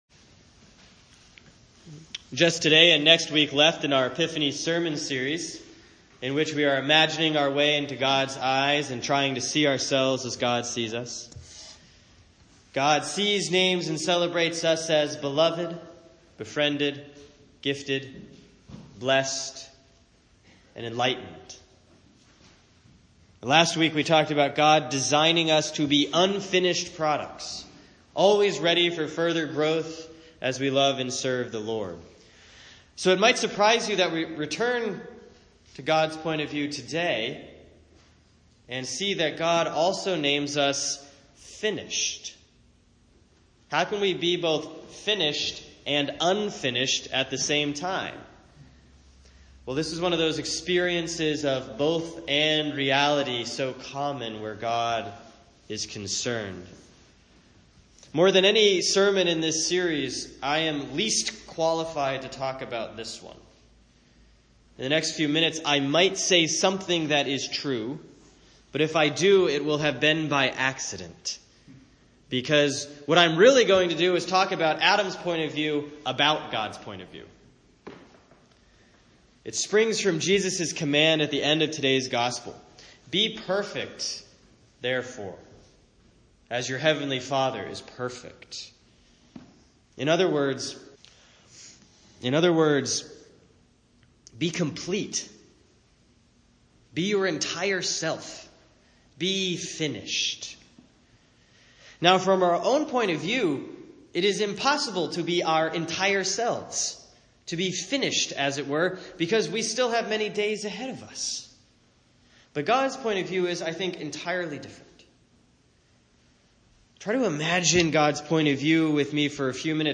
Week seven of the Epiphany sermon series on what God sees, names, and celebrates about us. This week: God names us FInished.